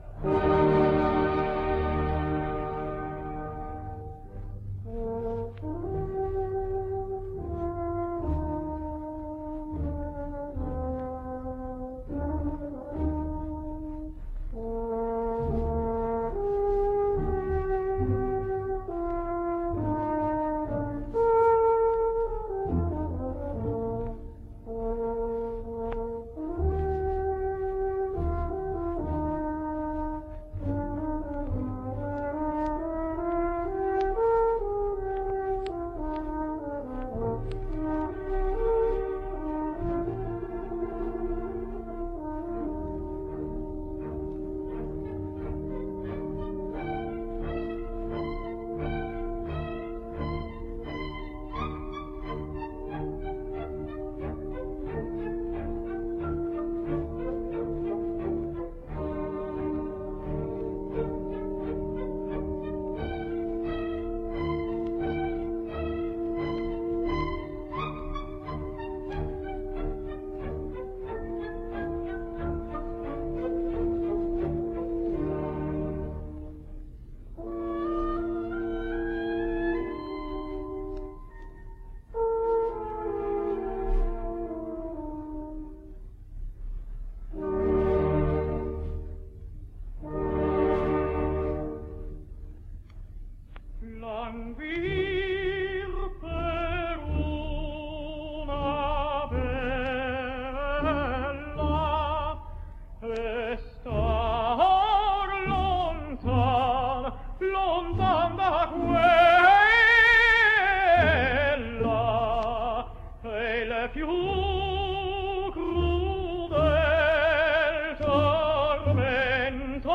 Italian Tenor.
A lyric tenor and a spinto, who were perhaps a little more celebrated and certainly more recorded than the rest.